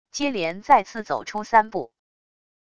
接连再次走出三步wav音频